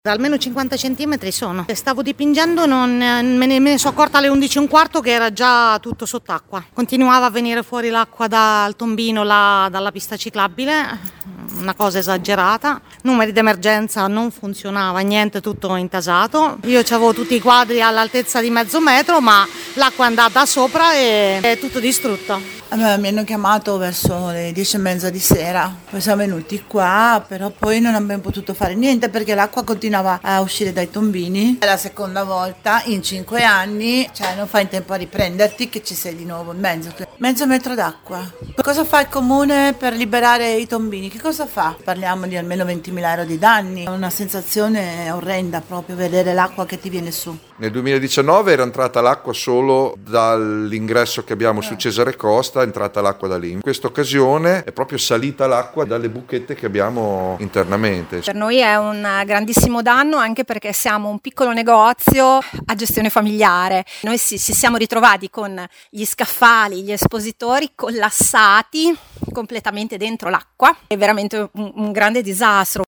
Qui sotto alcuni residenti e commercianti di via Cesare Costa e via Casoli